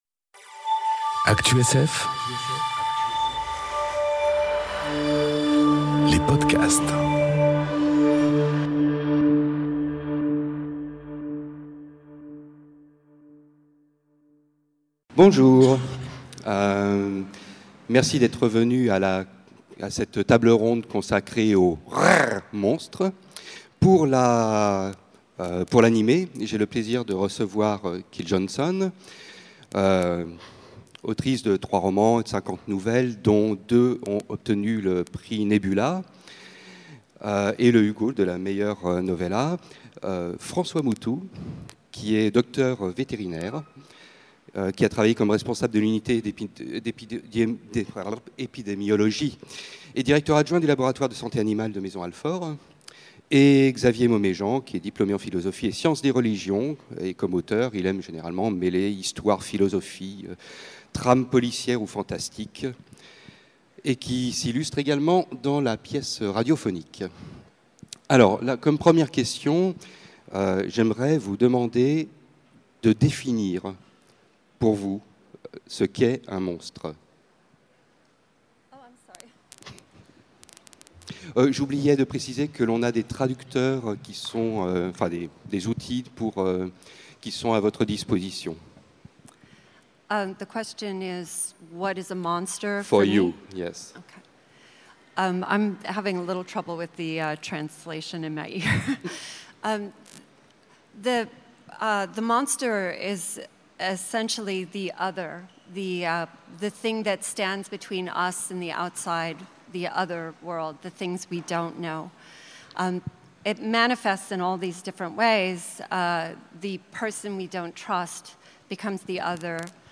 Conférence Le monstre enregistrée aux Utopiales 2018